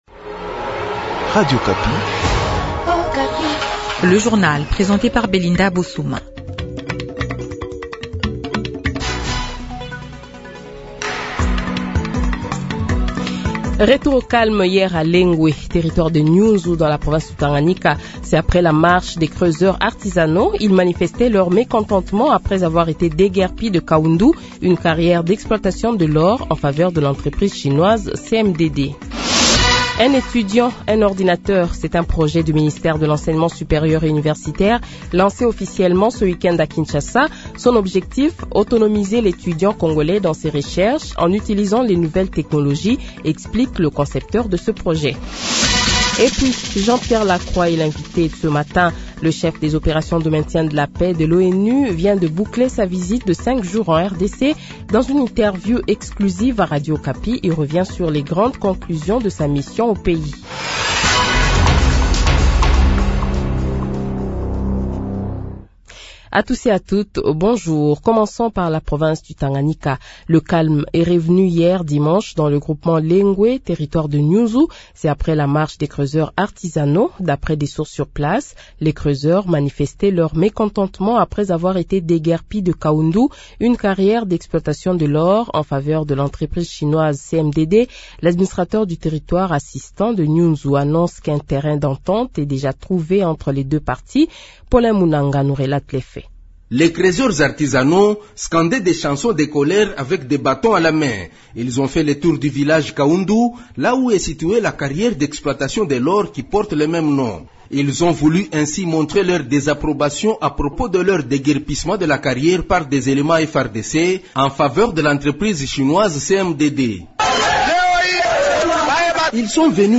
Journal Matin 8 heures